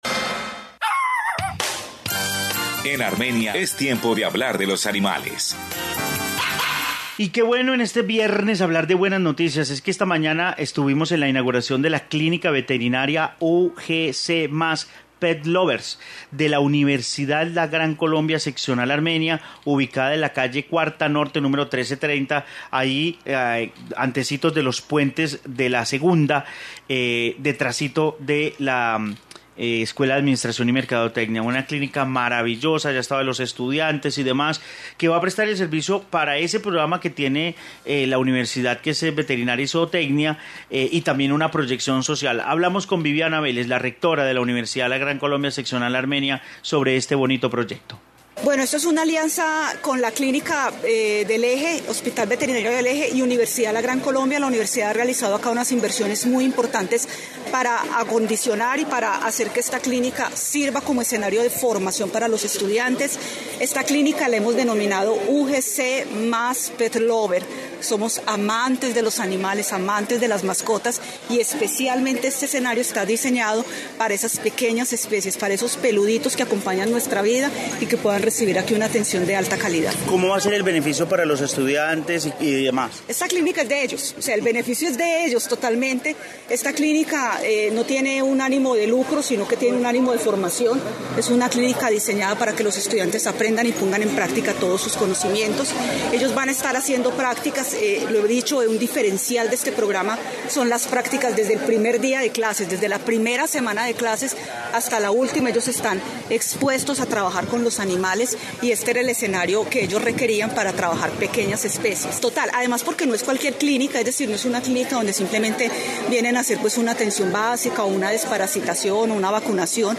Informe Clínica Veterinaria Universidad La Gran Colombia